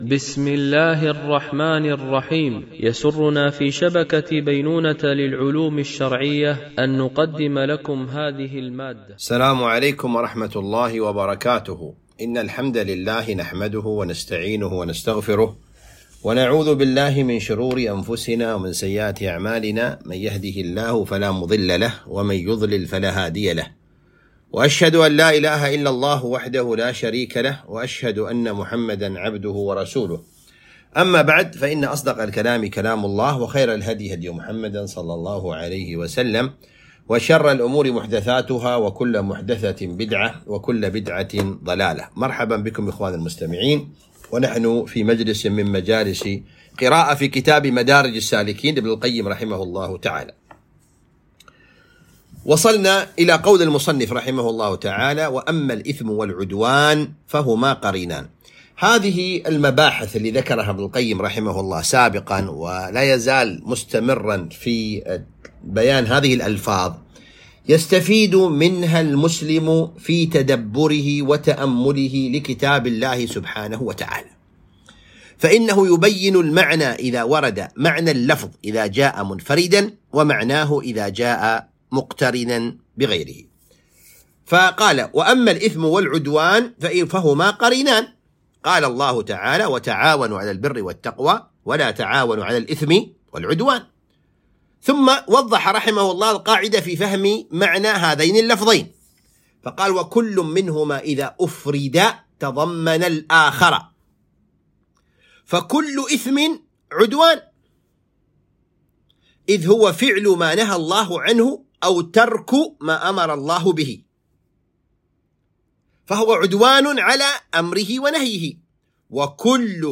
قراءة من كتاب مدارج السالكين - الدرس 39